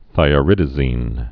(thīə-rĭdə-zēn)